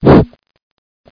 jump.mp3